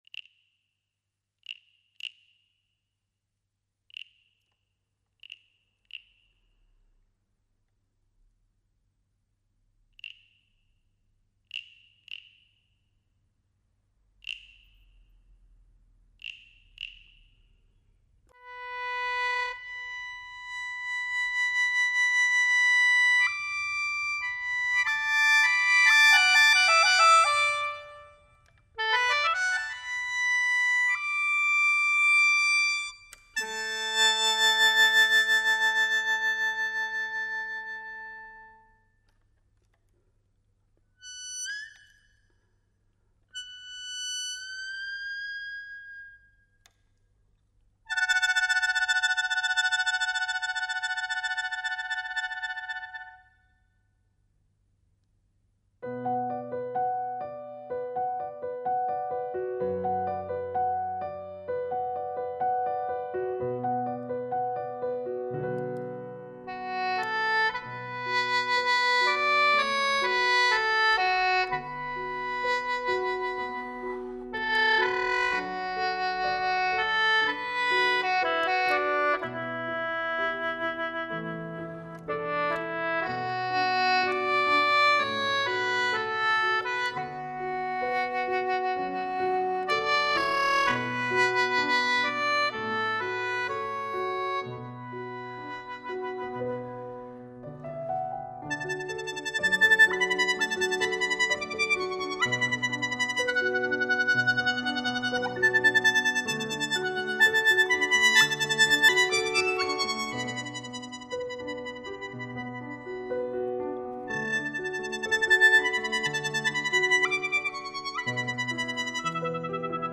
The sheng (Chinese mouth organ)